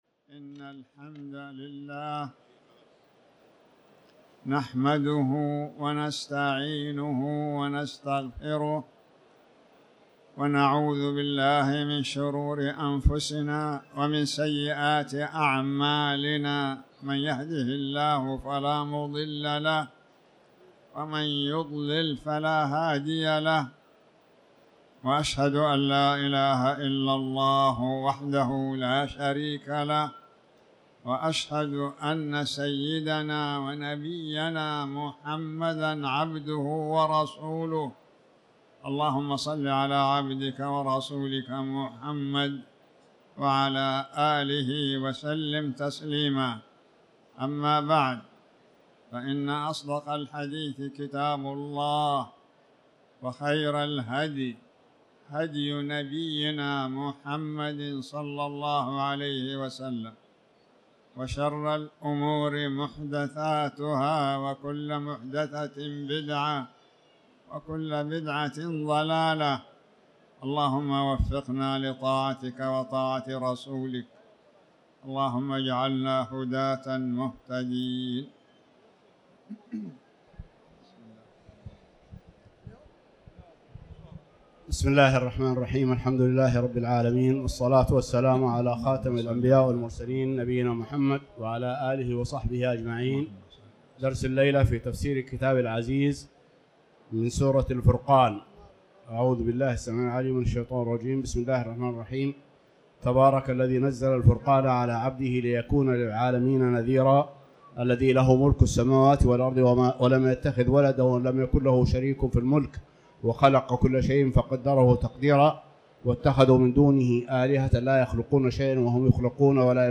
تاريخ النشر ١٣ جمادى الآخرة ١٤٤٠ هـ المكان: المسجد الحرام الشيخ